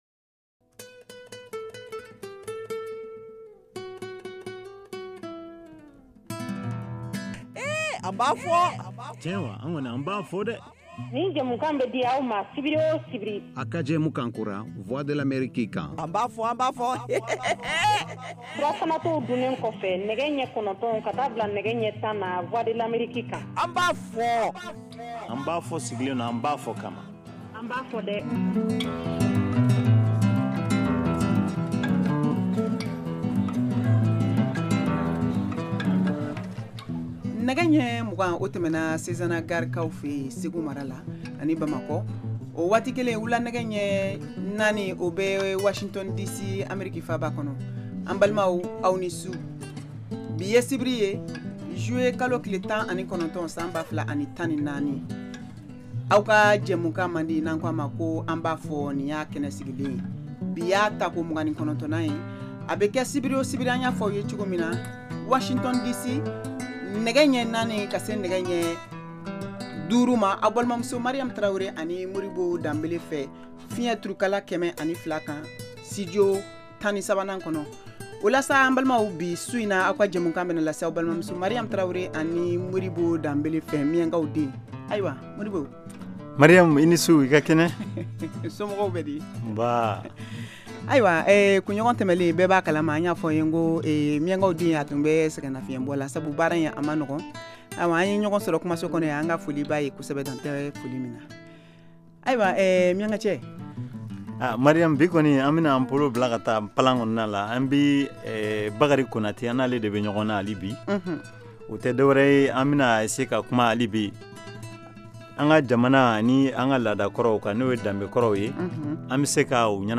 An ba fɔ! est une nouvelle émission interactive en Bambara diffusée en direct tous les samedis, de 20:00 à 21:00 T.U.